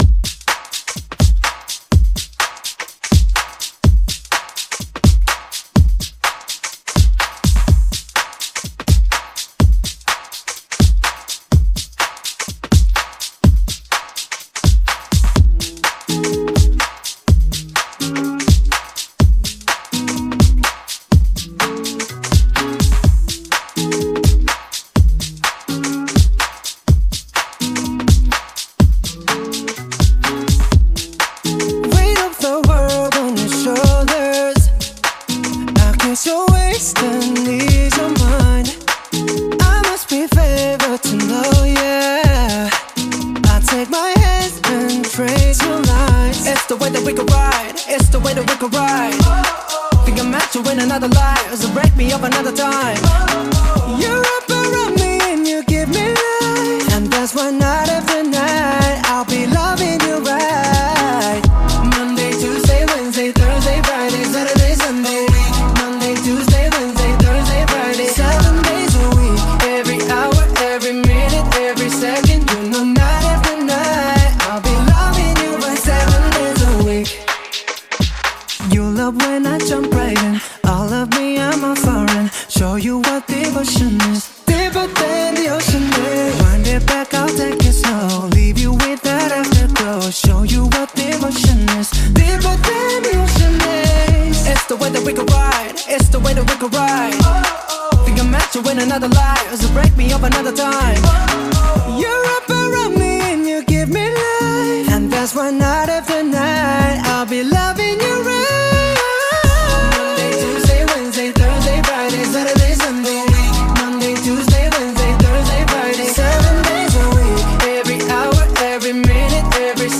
Genre: RE-DRUM
Clean BPM: 125 Time